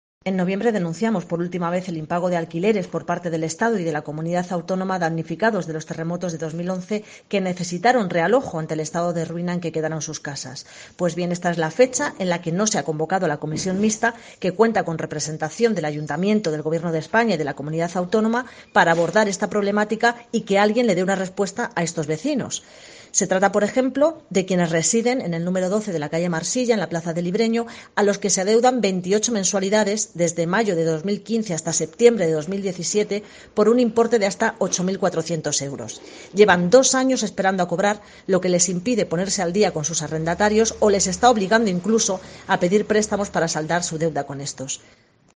Gloria Martín, edil de IU Verdes sobre ayudas terremotos